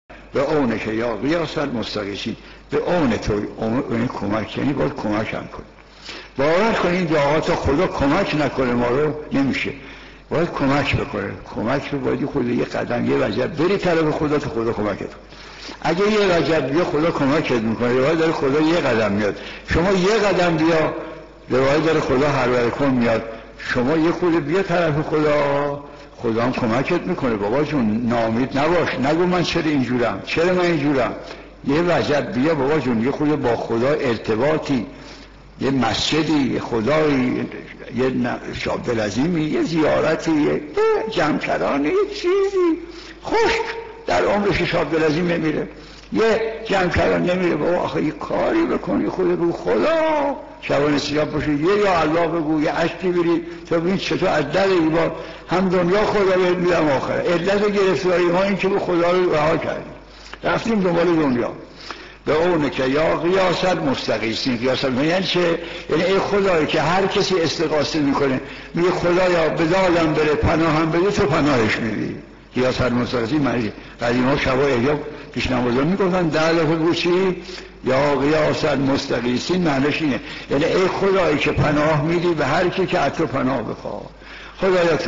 کلیپ کوتاهی با موضوع قدم برداشتن سمت خدا از سخنان گهر بار حضرت آیت الله مجتهدی تهرانی (ره) - بخشی از سخنرانی مربوط به شرح دعای روز یازدهم ماه مبارک رمضان